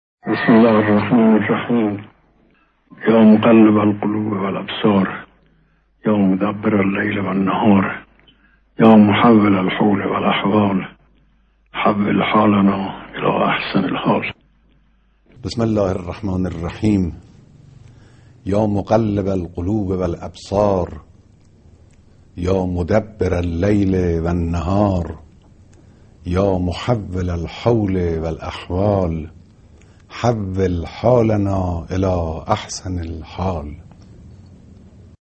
دعای تحویل سال با صدای امام و رهبری
دعای-تحویل-سال.mp3